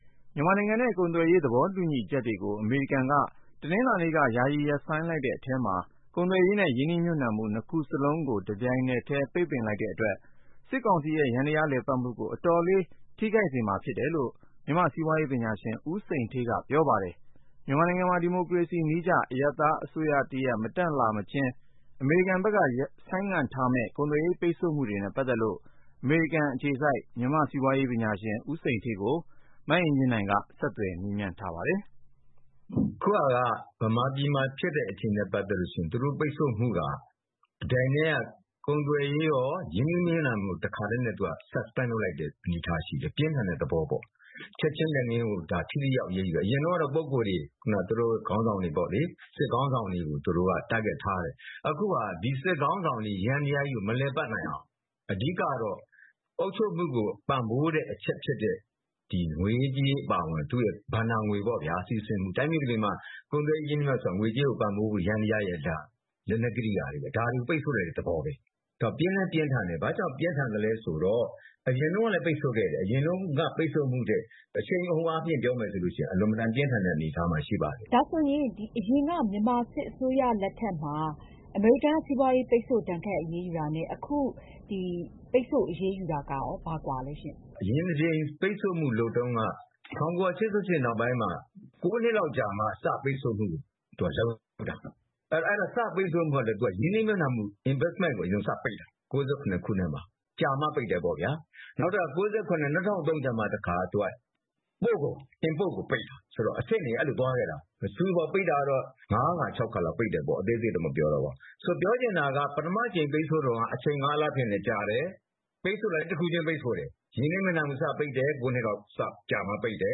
အမေရိကန် စီးပွားရေးဒဏ်ခတ်မှု စစ်ကောင်စီအပေါ် ဘယ်လောက်ထိရောက်မလဲ (ဆက်သွယ်မေးမြန်းချက်)
Skype ကနေ ဆကျသှယျမေးမွနျးထားပါတယျ။